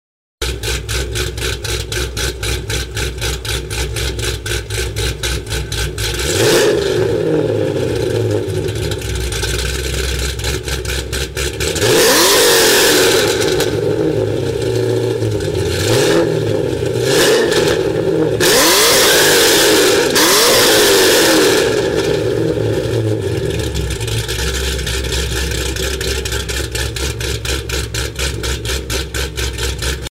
What do you think of the sound of this beast in the streets?
This is music to my ears, listen to it roar and enjoy my work 🔥🇻🇪 ‘68 Chevy Camaro!